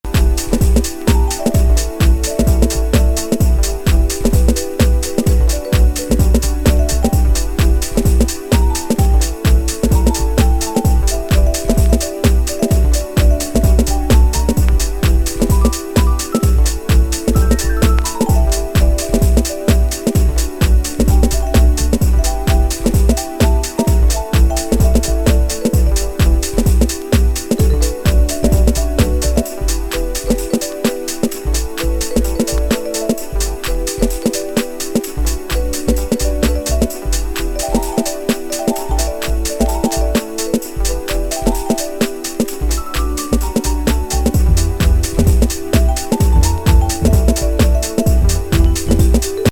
デトロイト・テクノとシカゴ・ハウスの間を埋めるテクノ・プロデューサー。